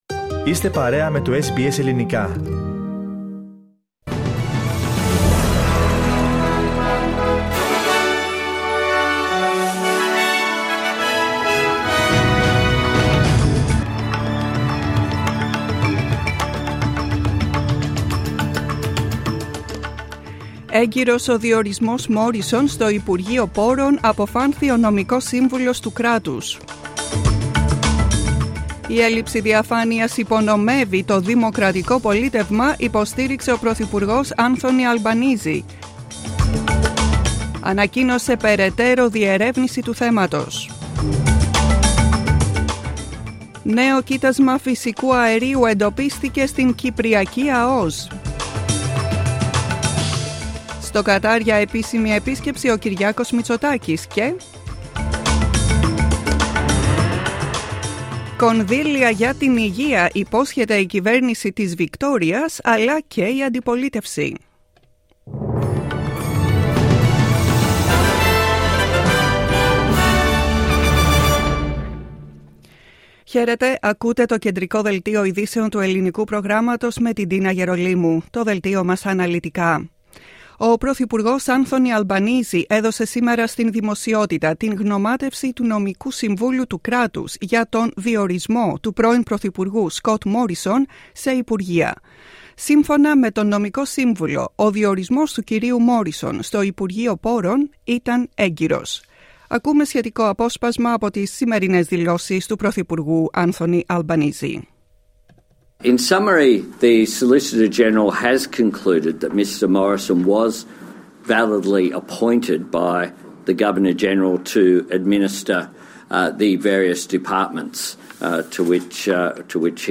Δελτίο ειδήσεων, 23 Αυγούστου 2022